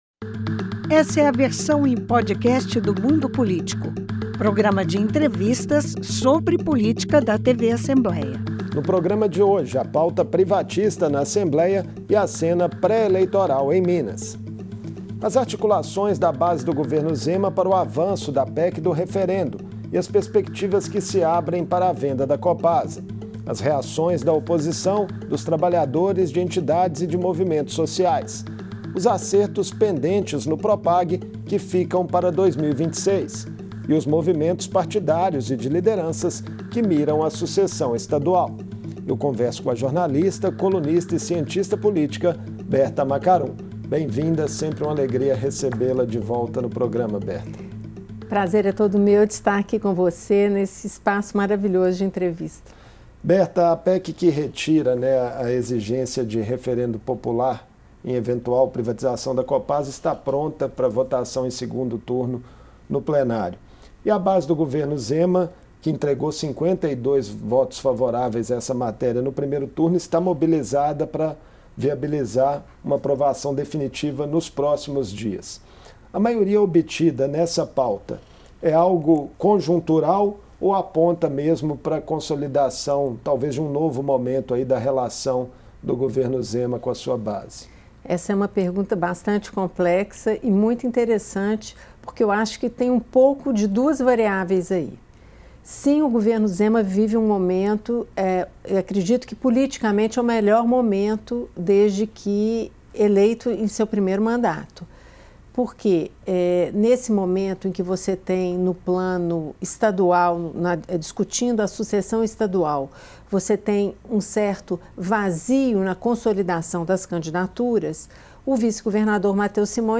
A derrubada do referendo popular que libera a privatização de estatais já cumpriu o 1º Turno e se a PEC for totalmente aprovada abre caminho para a venda da Copasa. Em entrevista